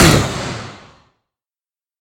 sounds / mob / wither / hurt1.ogg
hurt1.ogg